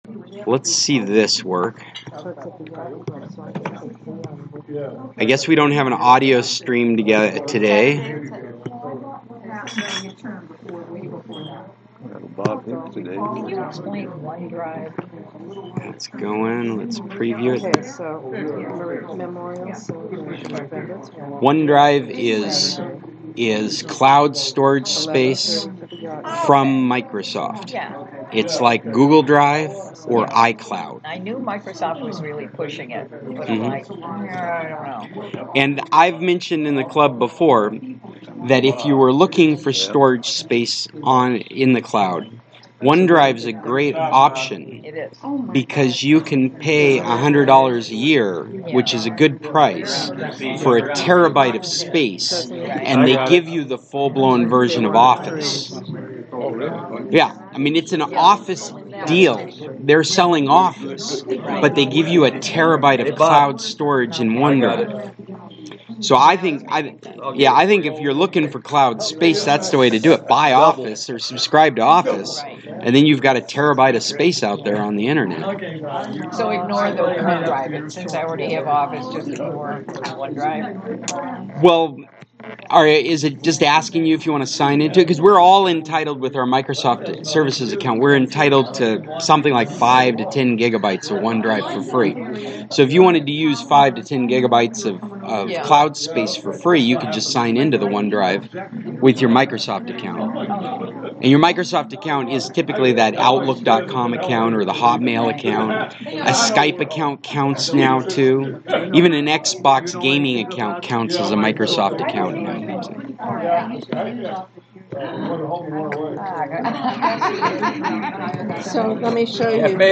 Lots of stuff today. 20 people showed up and all with topics.